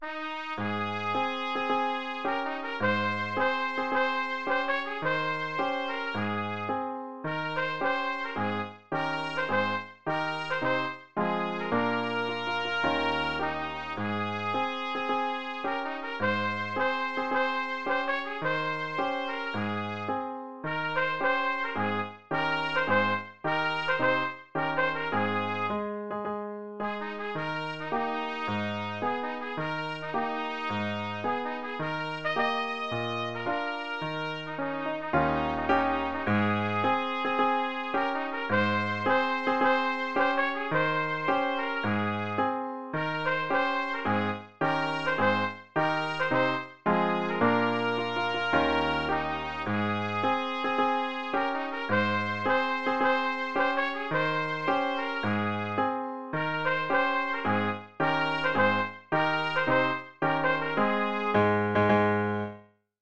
'Triumphal March' from Aida (Verdi) | Free Easy Trumpet Sheet Music (Digital Print)
Free printable sheet music for Triumphal March from Aida for Easy Trumpet Solo with Piano Accompaniment.
triumphal-march-trumpet-solo.mp3